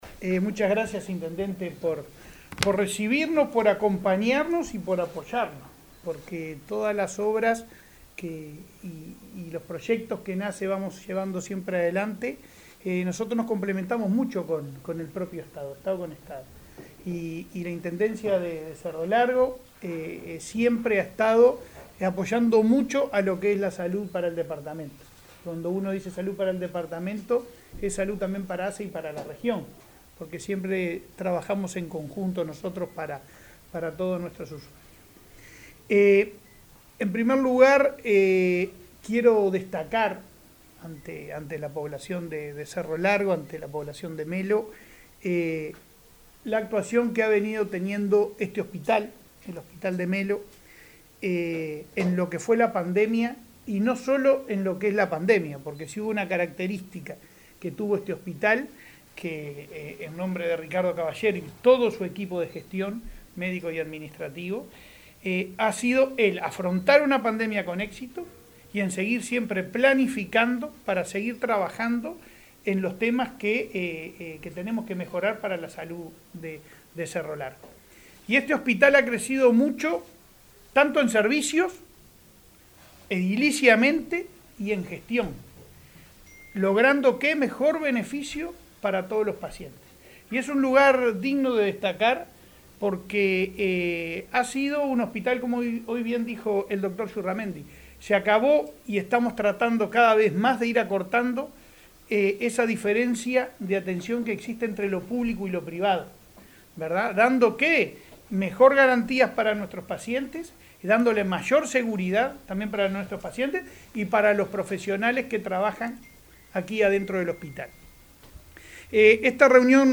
Palabras del presidente de ASSE, Leonardo Cipriani